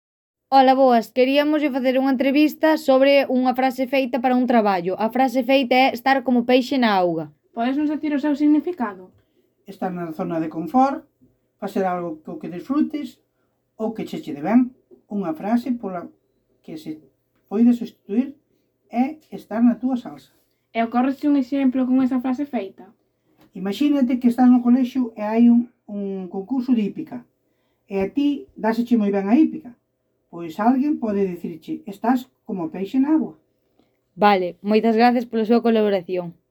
Entrevista-peixe-na-auga.mp3